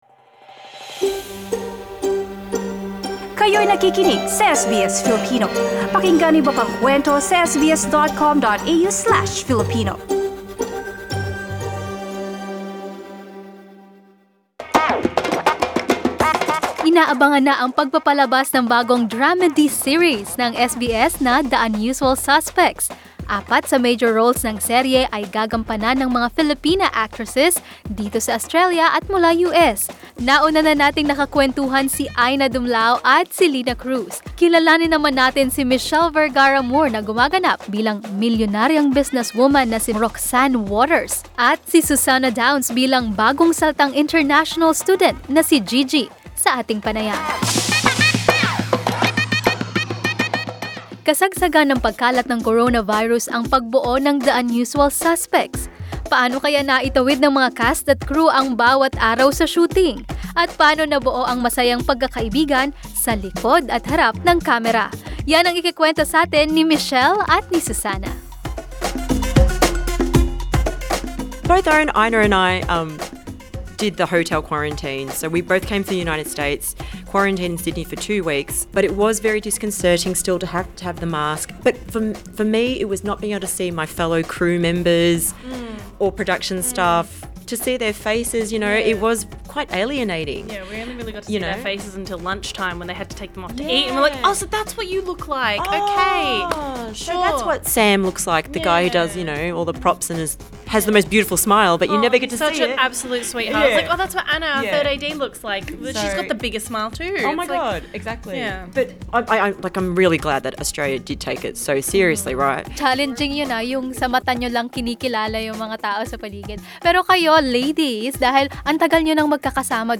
SBS Filipino sits down with the cast of The Unusual Suspects , a four-part drama-comedy series with a cast that features Filipino-Australian actors.